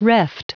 Prononciation du mot reft en anglais (fichier audio)
Prononciation du mot : reft